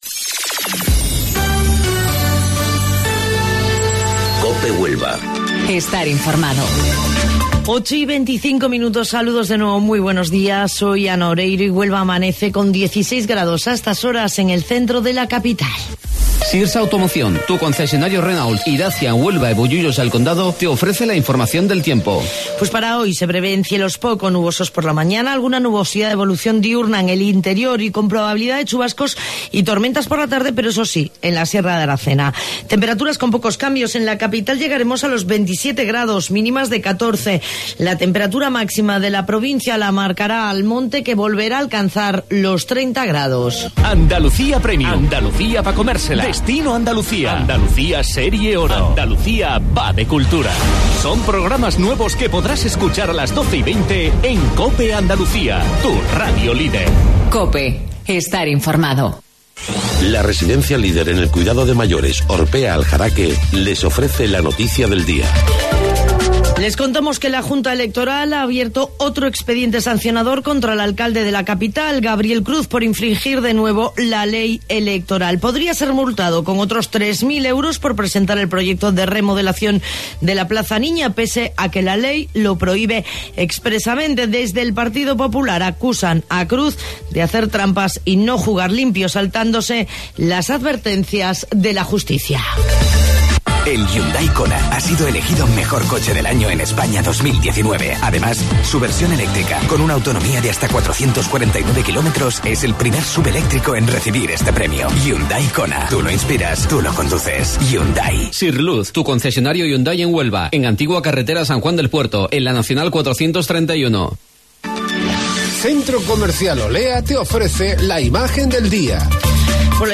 AUDIO: Informativo Local 08:25 del 2 de Mayo